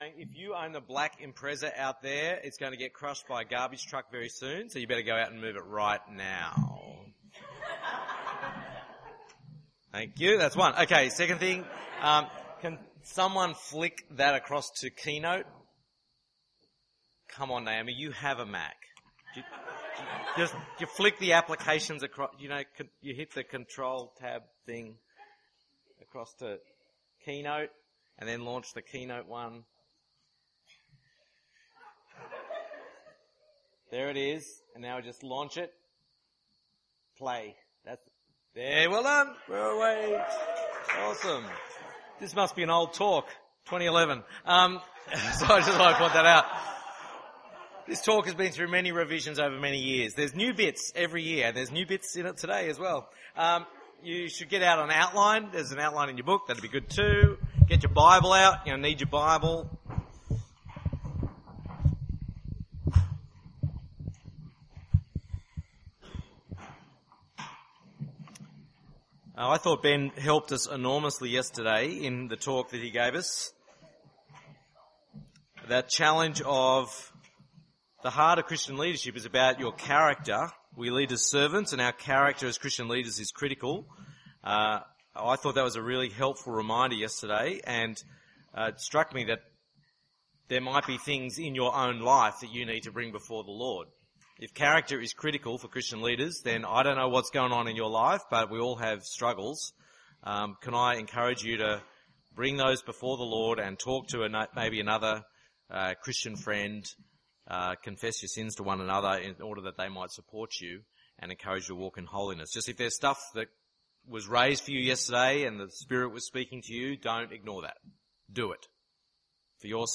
Presidential Address